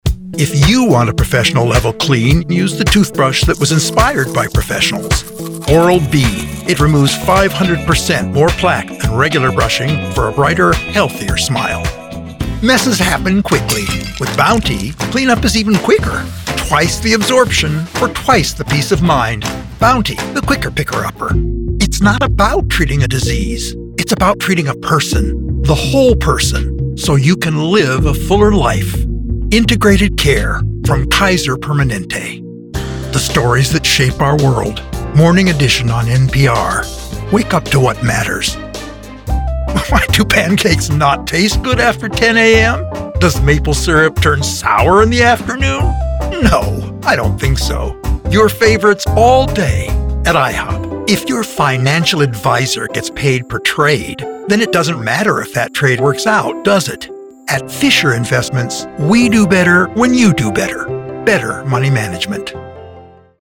commercial
Voice Age
Middle Aged